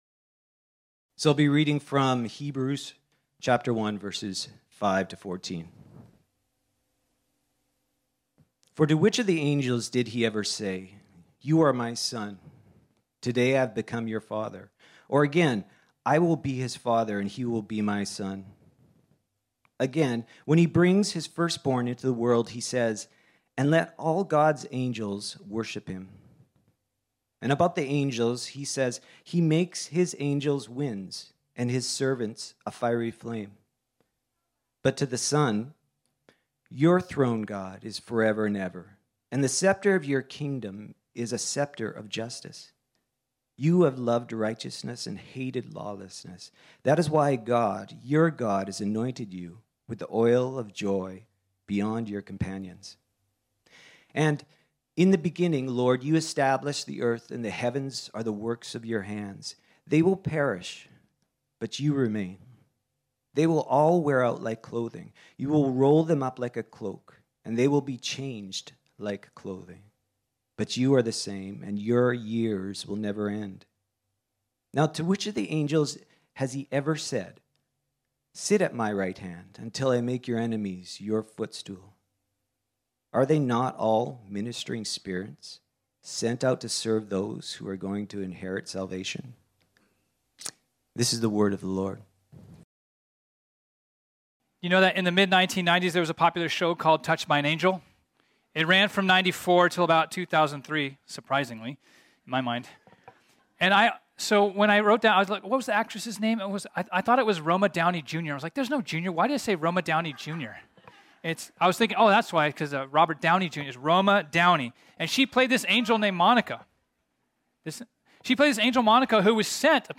This sermon was originally preached on Sunday, September 18, 2022.